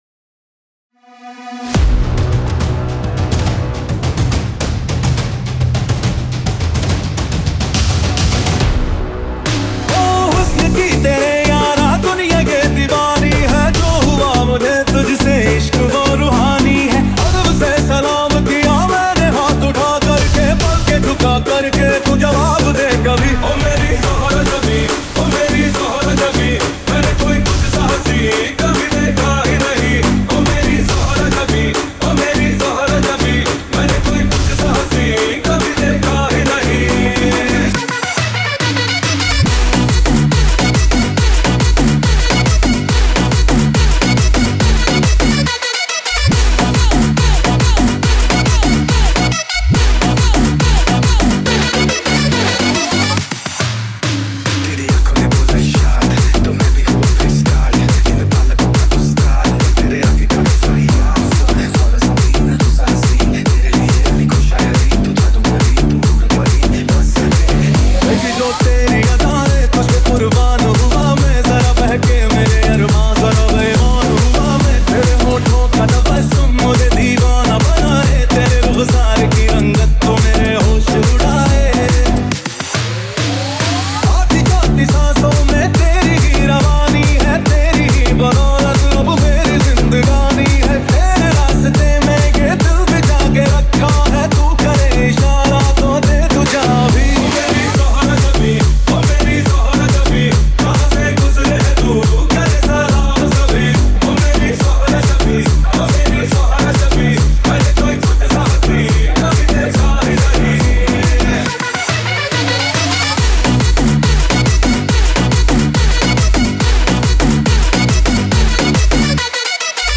Circuit Mix